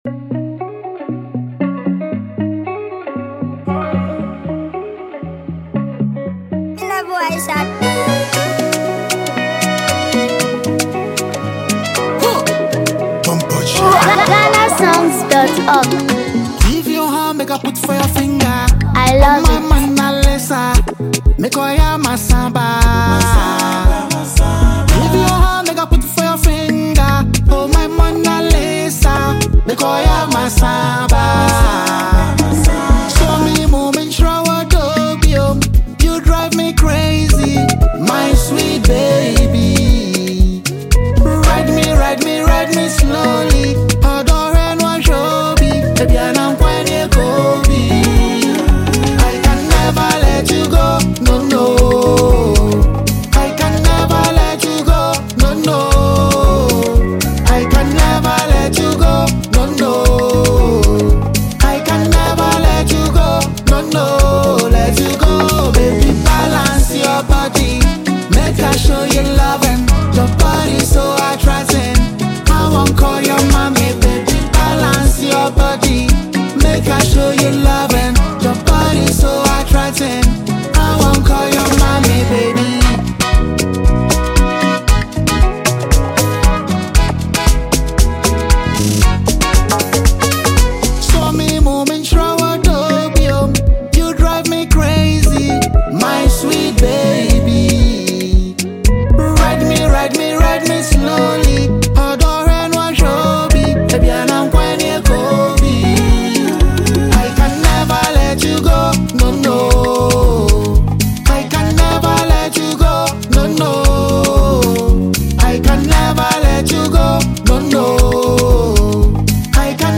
Ghanaian singer
Known for his smooth vocals and heartfelt songwriting
blends Afrobeats, highlife, and modern Ghanaian sound